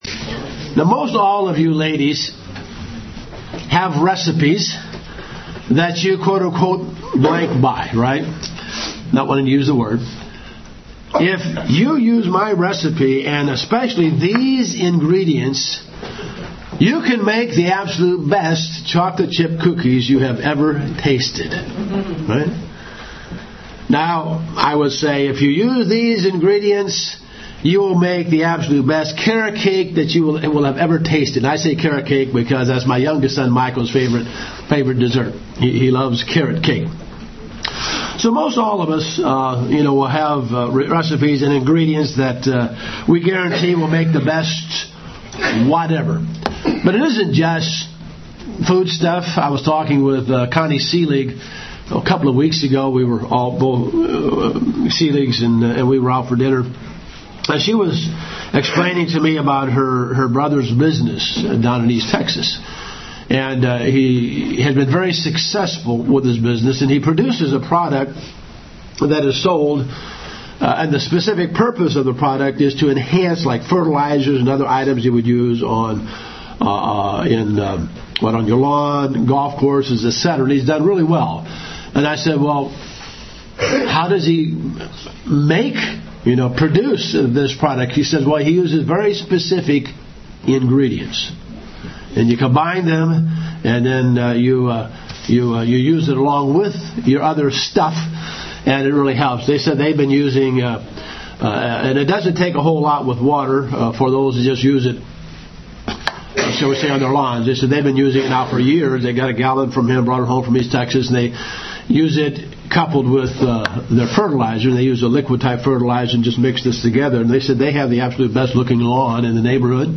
Several Ingredients can make failure assured. This sermon explains several of the ideas or choices a person can make to guarantee failure.